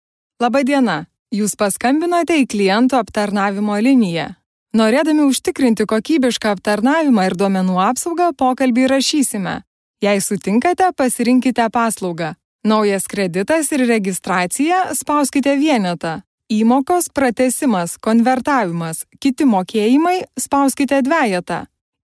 Lithuanian female voice over